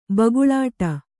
♪ baguḷāṭa